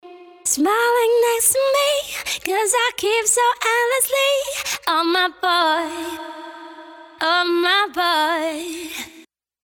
Wie wäre es beispielsweise mit ein wenig Menschlichkeit in Form von betörendem Gesang?